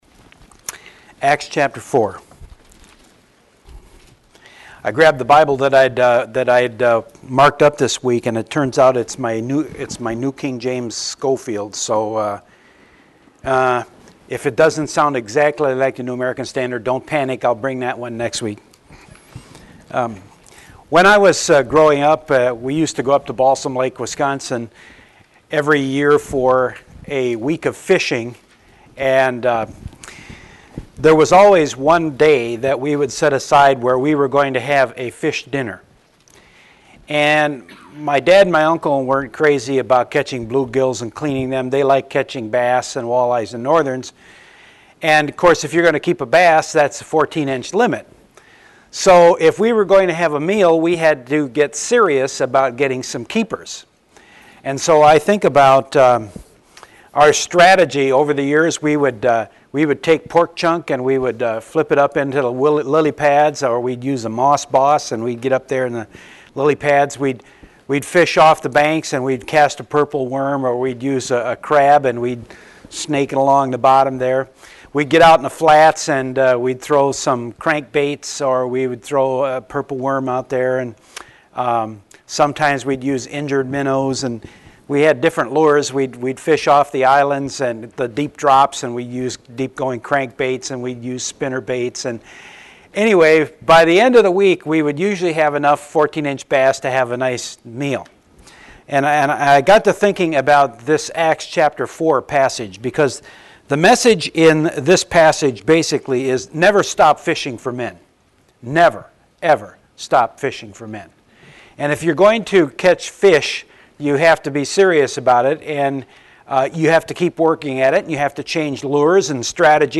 Sermons | Fishing with Grace and Truth